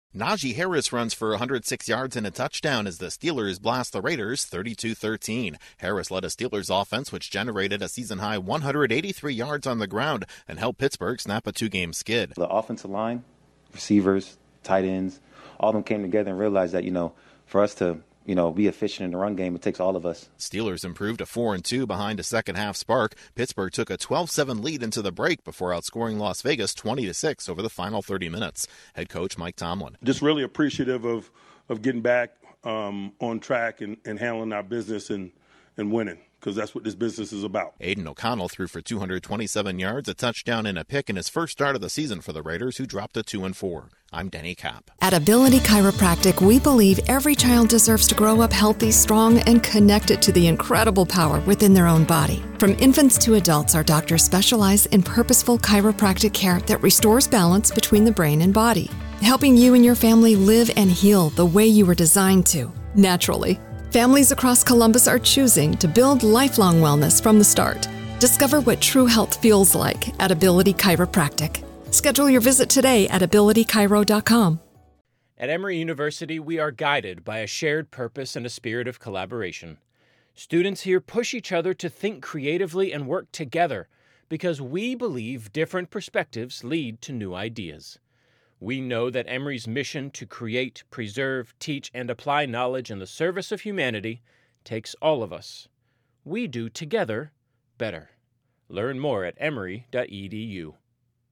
The Steelers overcome a sluggish start to roll past the Raiders. Correspondent